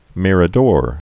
(mĭrə-dôr)